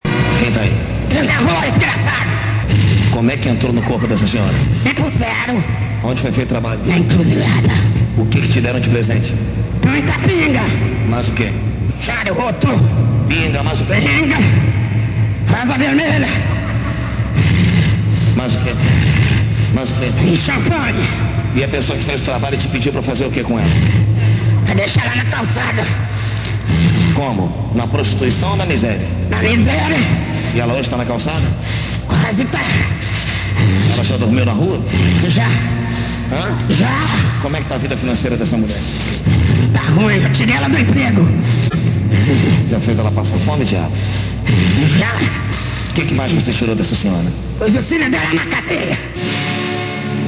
Entrevistas com demônios (áudio)